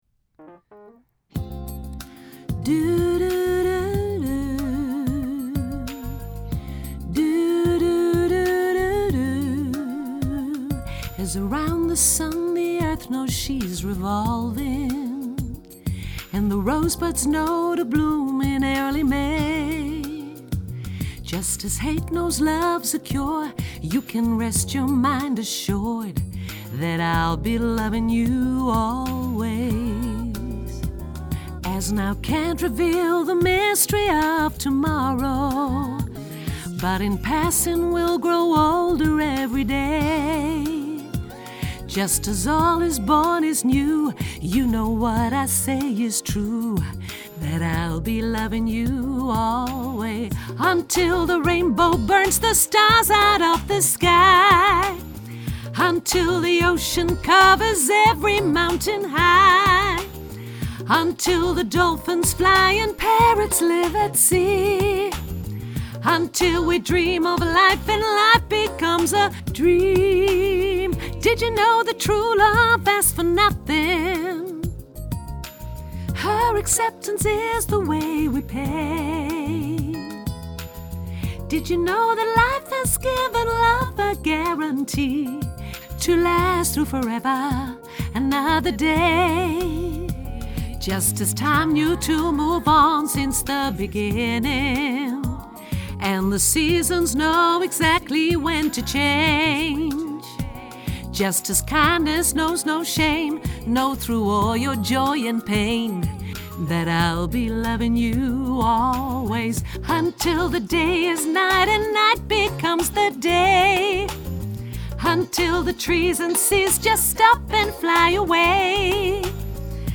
alt hoog